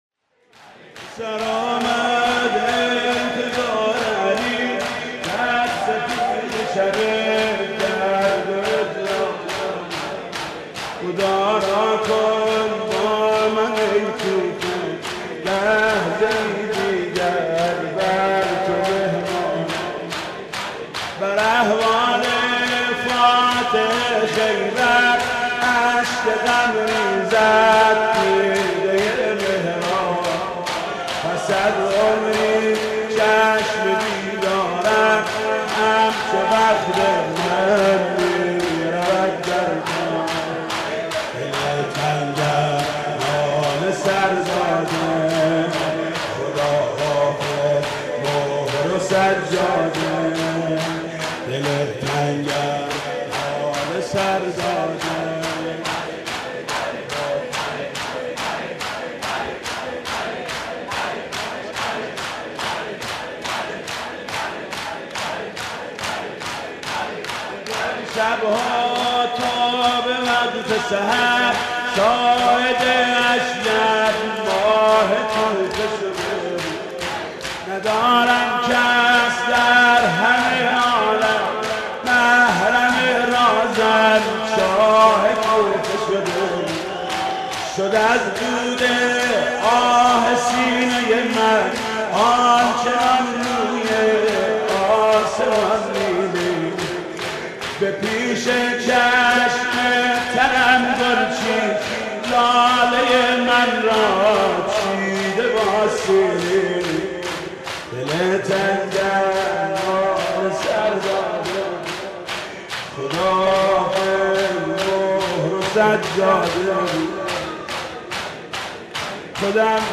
شور: به سر آمد انتظار علی